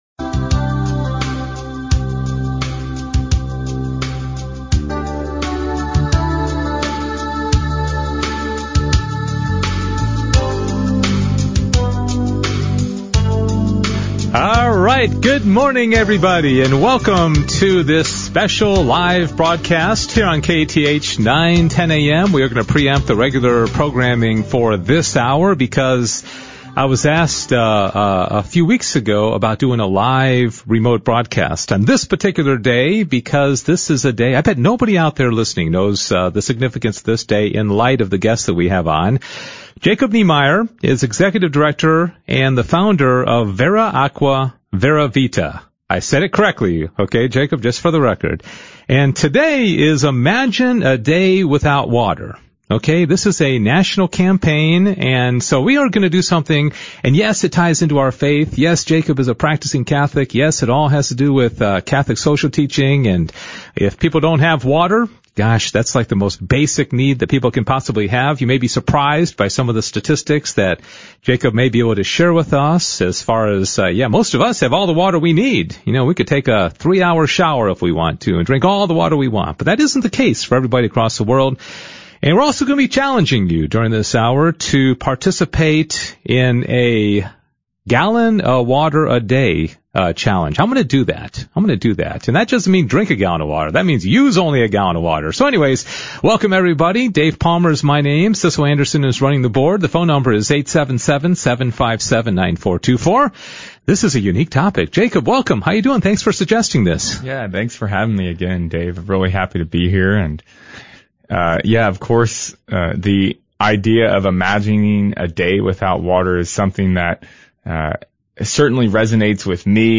October 21, 2020 | Live On-Air Radio Interview